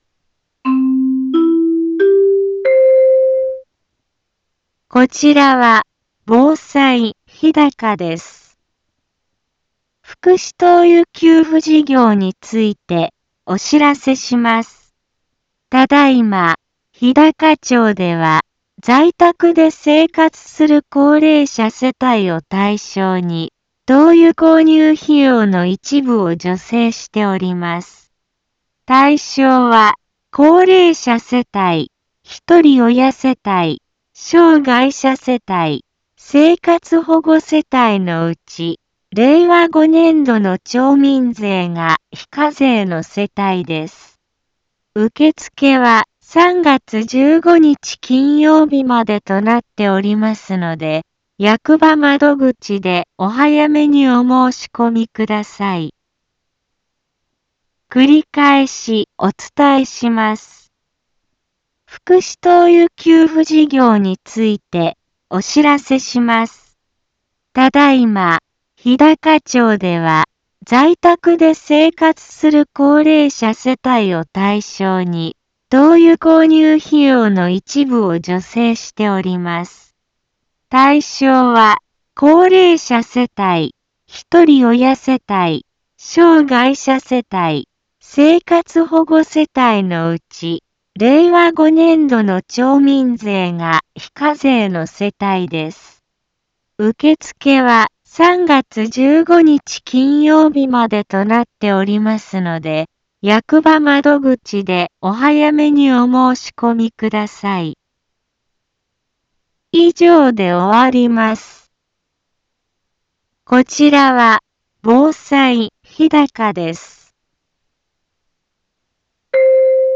一般放送情報
Back Home 一般放送情報 音声放送 再生 一般放送情報 登録日時：2024-02-05 10:04:18 タイトル：福祉灯油事業のお知らせ インフォメーション： 福祉灯油給付事業についてお知らせします。 ただ今、日高町では在宅で生活する高齢者世帯を対象に灯油購入費用の一部を助成しております。